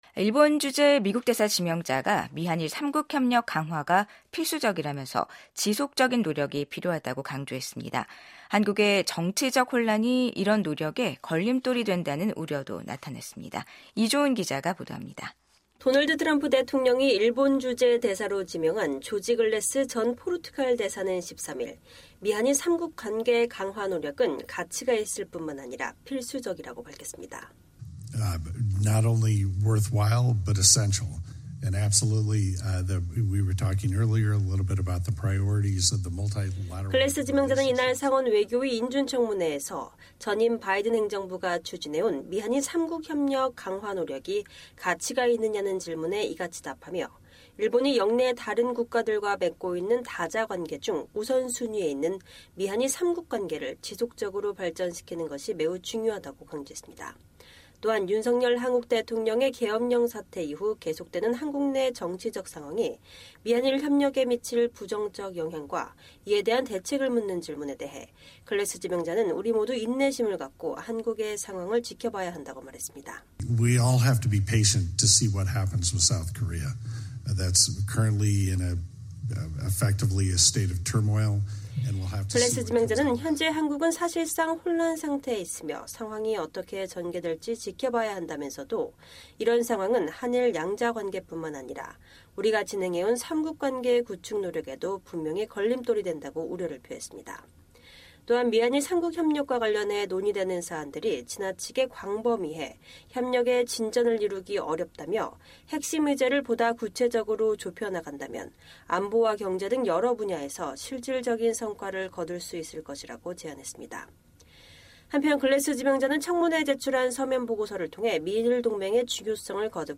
조지 글래스 일본 주재 미국 대사 지명자가 2025년 3월 13일 상원 외교위 인준청문회에서 발언하고 있다.